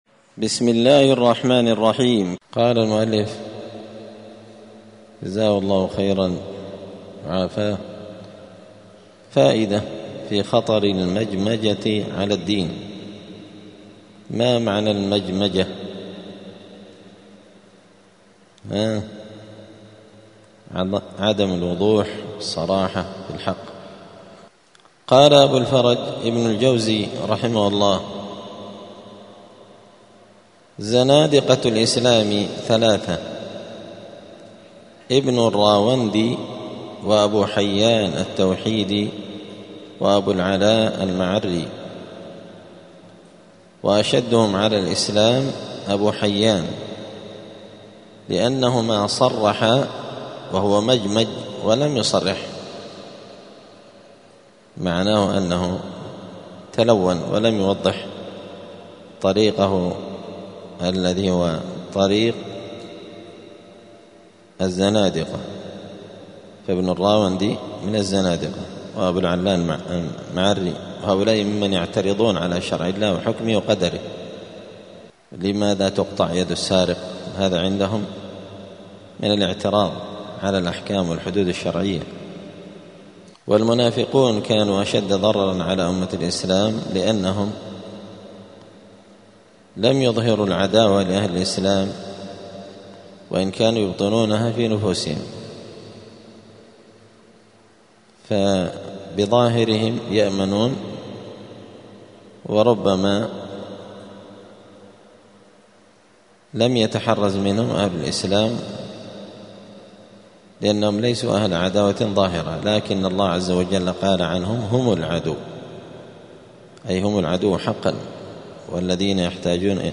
دار الحديث السلفية بمسجد الفرقان بقشن المهرة اليمن
*الدرس الثامن والستون (68) {فائدة في خطر المجمجة على الدين}*